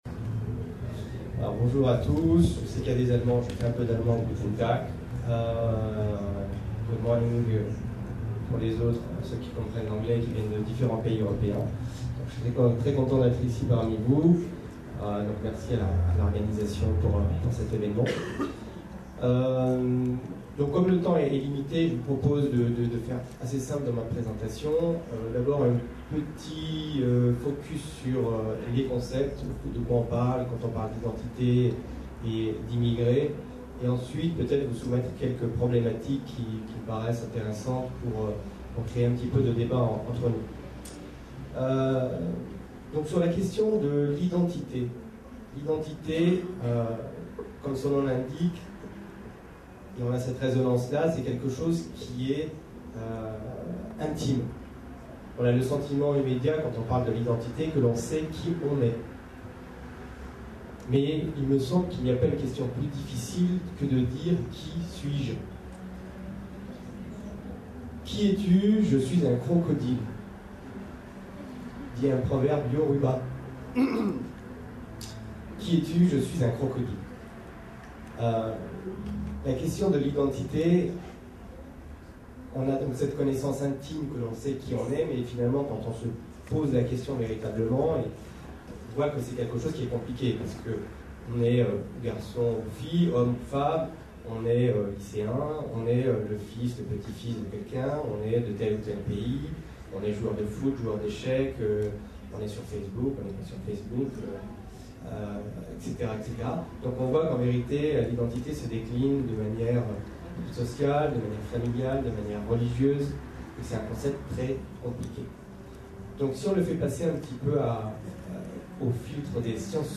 Une conférence